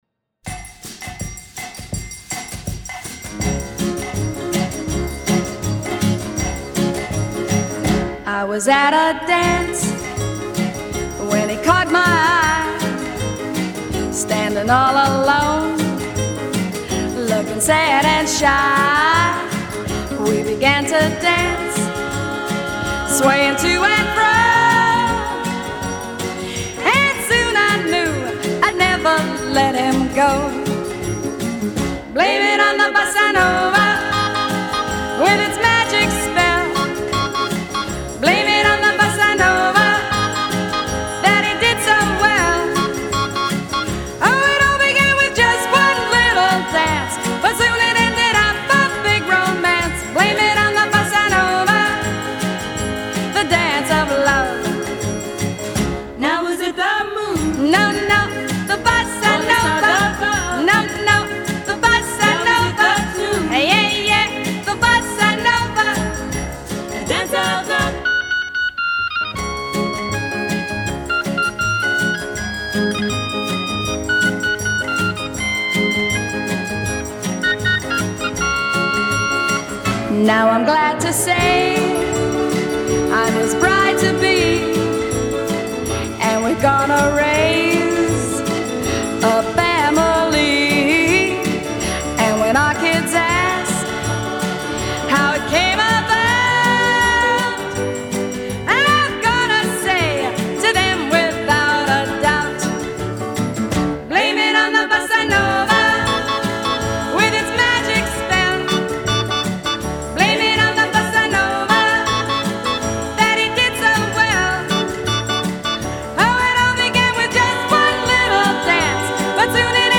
Качество звучания отличное.